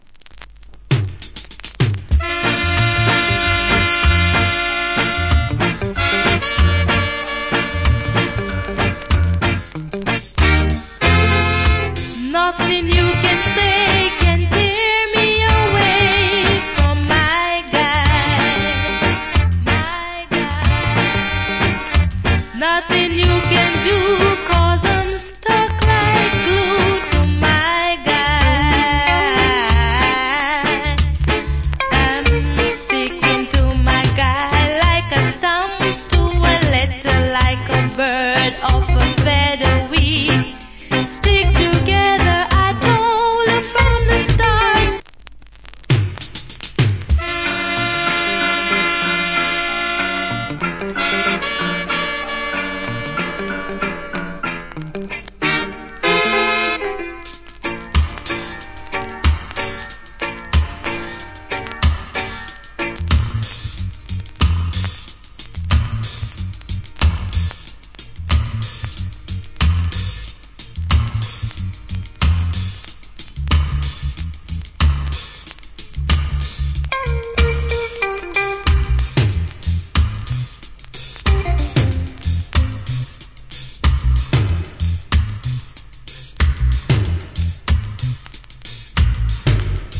Good Female Reggae Vocal.